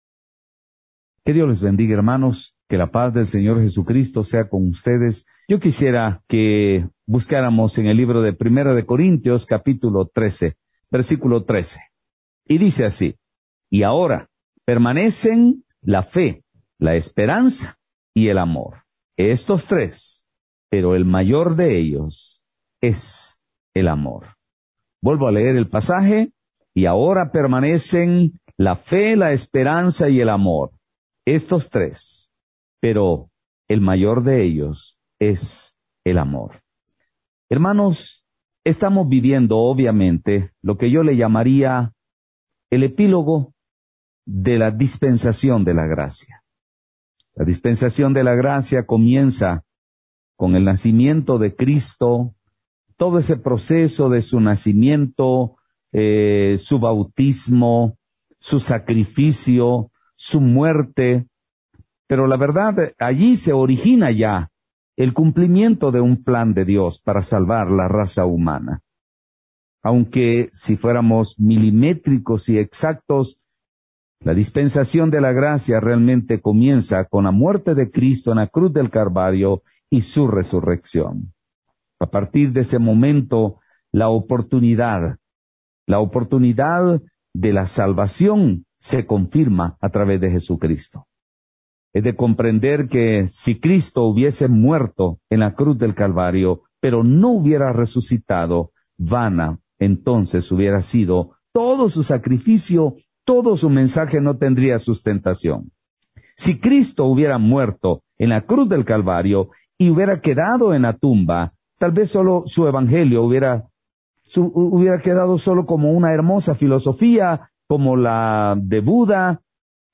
Series: Servicio General